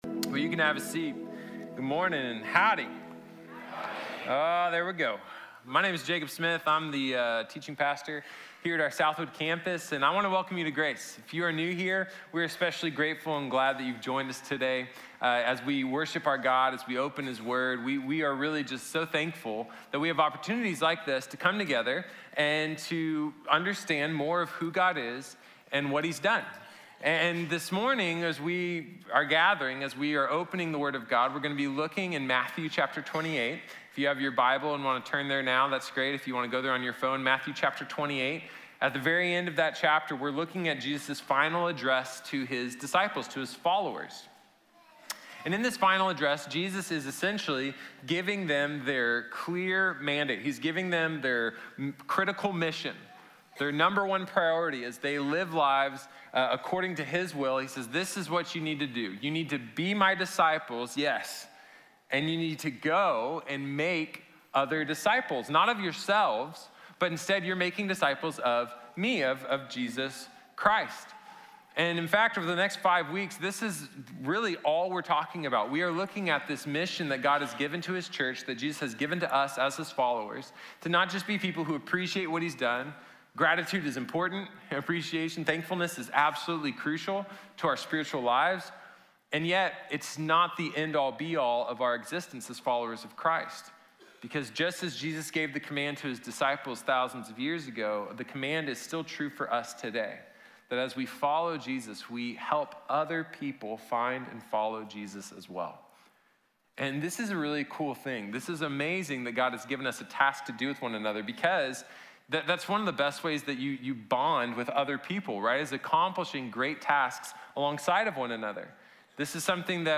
Make disciples | Sermon | Grace Bible Church